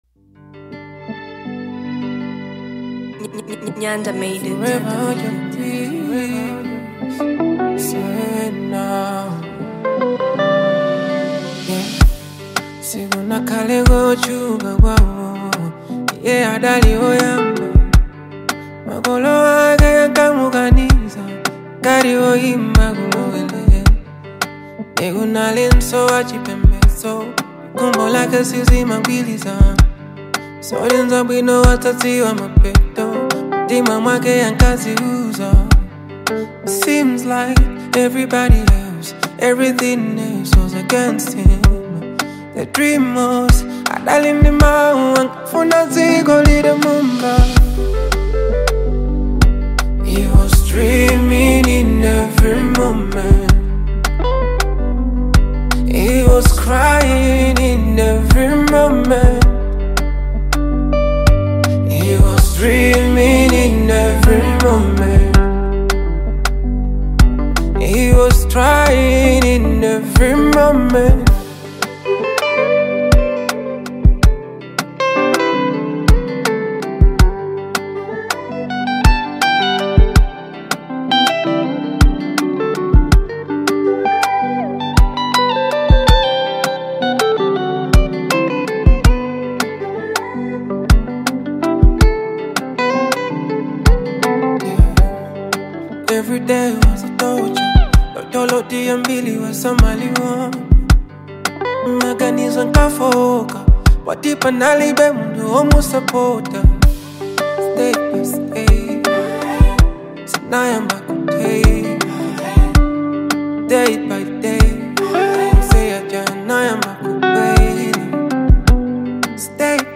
emotionally charged
With its infectious beat and captivating vocals